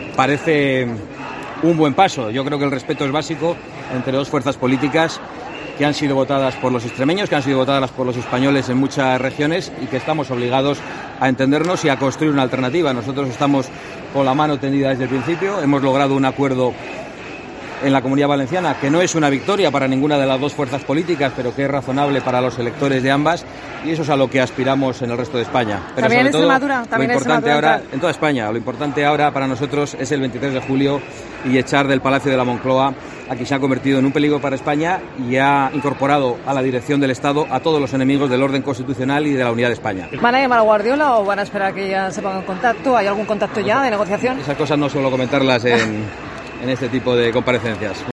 Así lo ha manifestado en declaraciones a los medios, a su llegada para asistir a la sesión constitutiva de las XI Legislatura en Les Cortes Valencianas, preguntado por la carta dirigida a los militantes en la que Guardiola ha aplazado hasta nueva fecha la Junta Directiva Autonómica y el Comité Ejecutivo Regional tras las fallidas negociaciones con Vox evidenciadas en la sesión constitutiva de la Asamblea en la que el PSOE se hizo con su Presidencia.